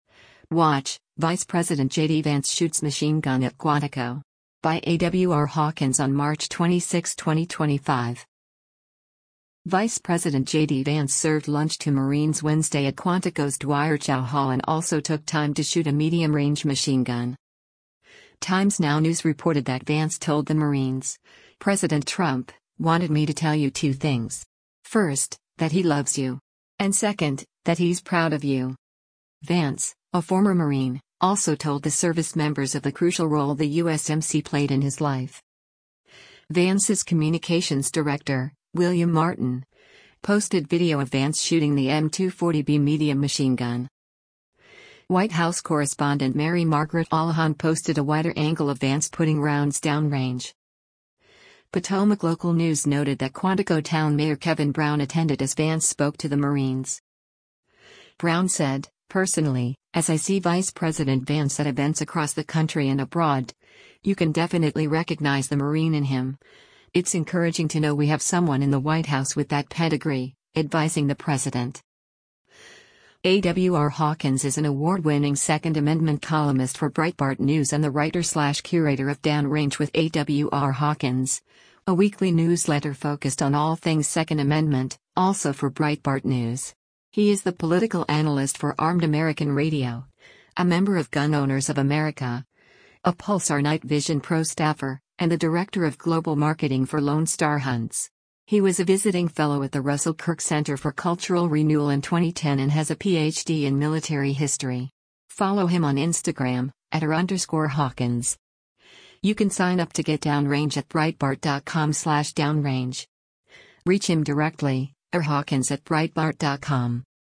WATCH: Vice President JD Vance Shoots Machine Gun at Quantico
U.S. Vice President JD Vance (L) shoots with U.S. Marines at a gun range during a visit to